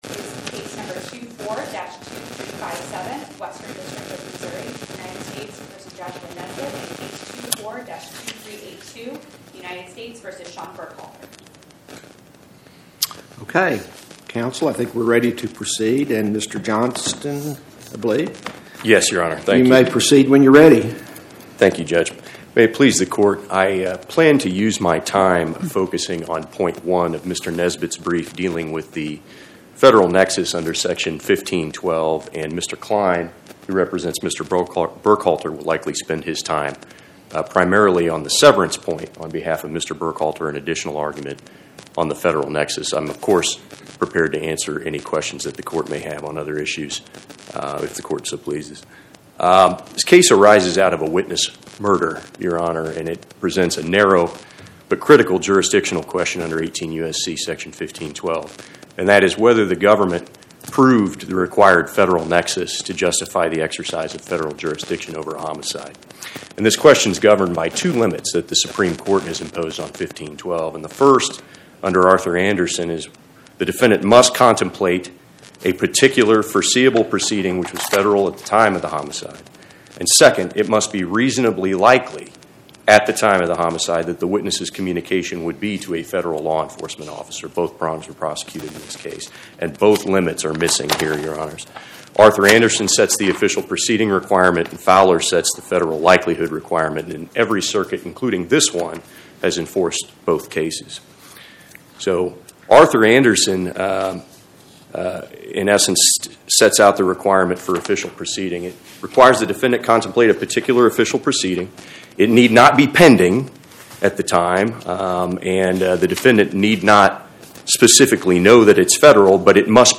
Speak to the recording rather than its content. Oral argument argued before the Eighth Circuit U.S. Court of Appeals on or about 01/15/2026